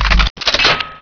range_rocket.wav